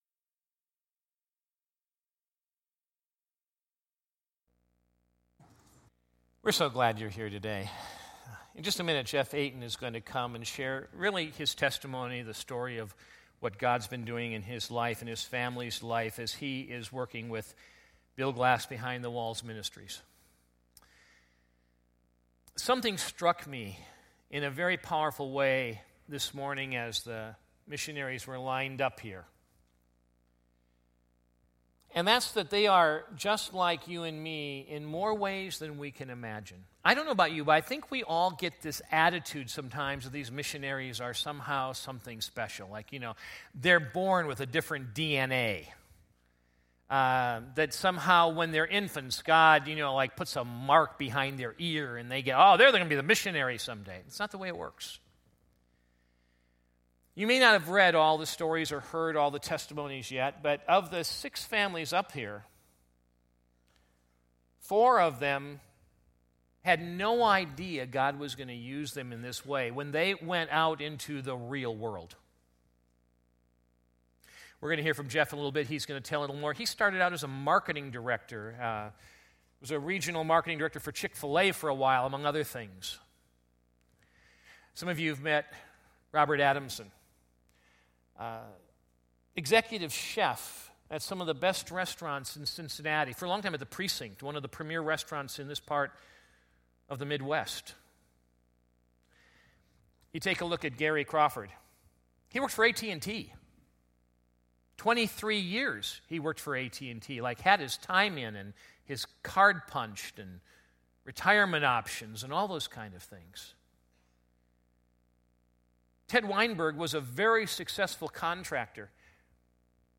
2016 Categories Sunday Morning Message Special Event Download Audio Bill Glass Behind the Walls